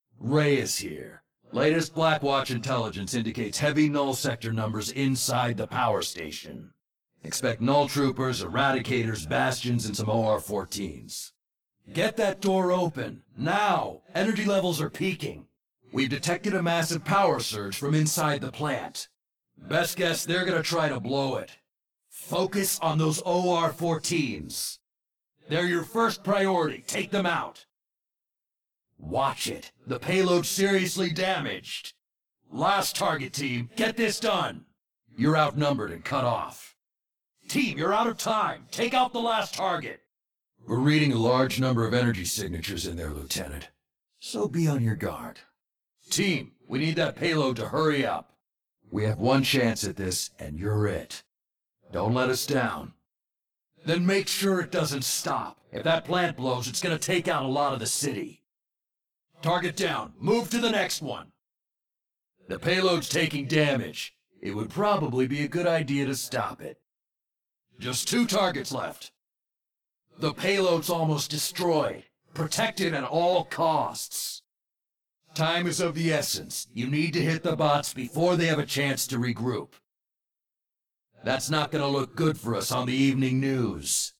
Here are all of Reyes’ voicelines from the Uprising event that I could find and extract, now that we have the key